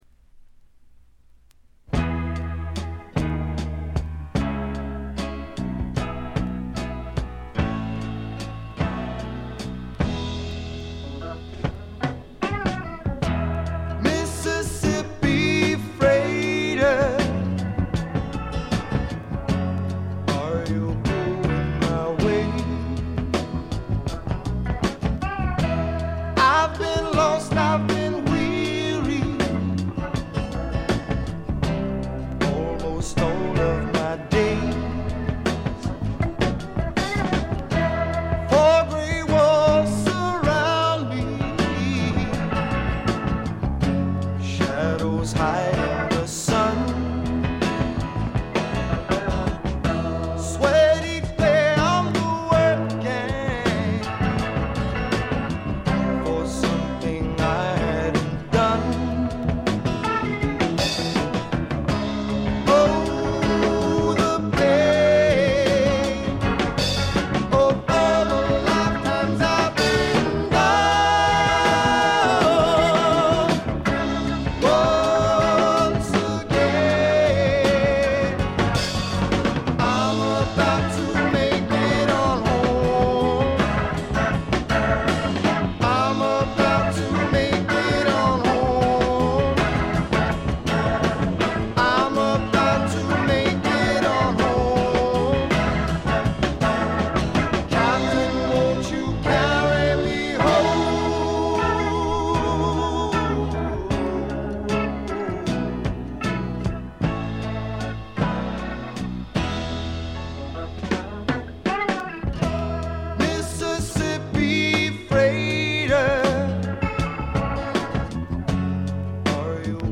で内容はというとザ・バンドからの影響が色濃いスワンプ裏名盤であります。
試聴曲は現品からの取り込み音源です。